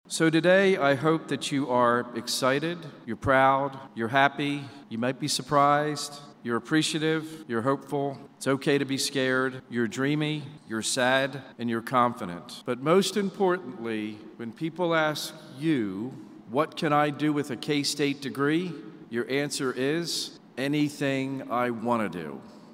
K-State President Richard Linton delivered the commencement speech to the Graduate School  Friday afternoon at Bramlage Coliseum.